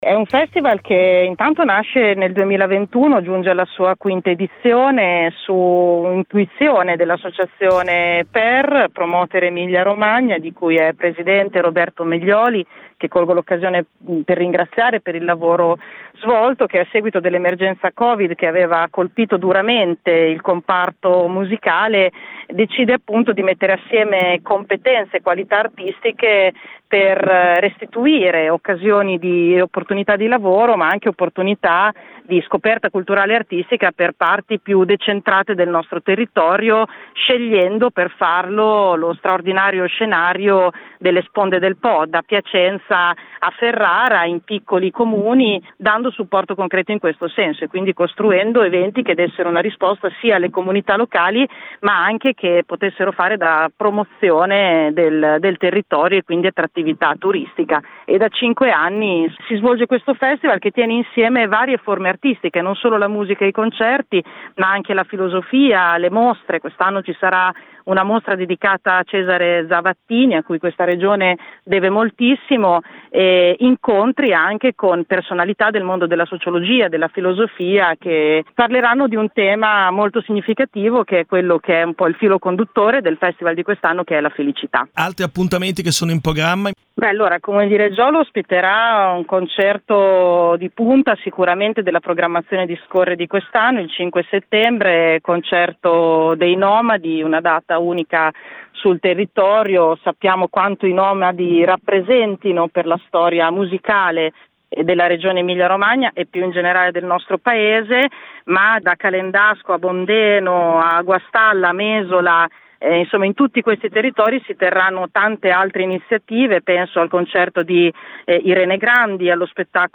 SCORRE-2025-ASSESSORA-REGIONALE-GESSICA-ALLEGNI.mp3